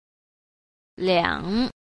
8. 兩 – liǎng – lưỡng (hai)